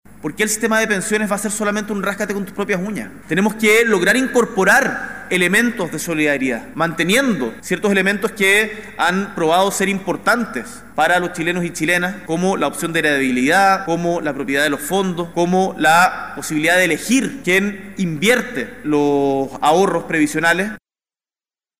En la ceremonia las autoridades celebraron algunas de las medidas que se han aprobado en el año en materia laboral, siendo la más reciente y una de las más emblemáticas del Gobierno, la Ley 40 horas.